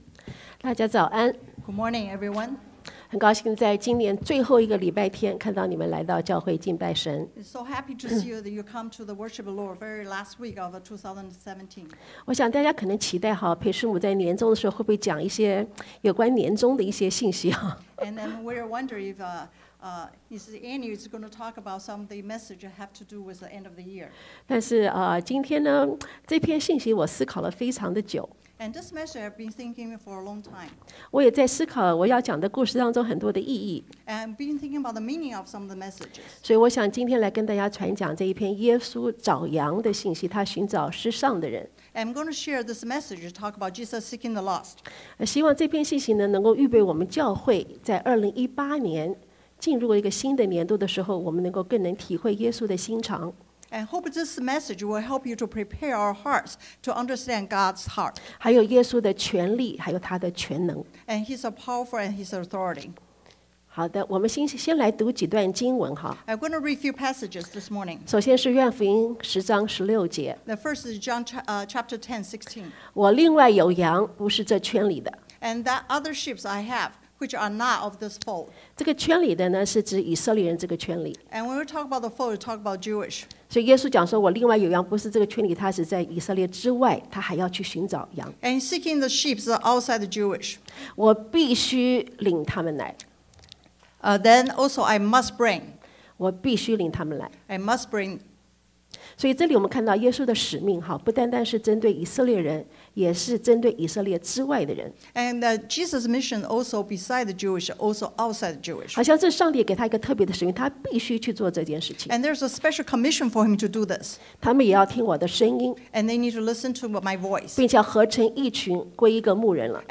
Service Type: Sunday AM
Bilingual Sermon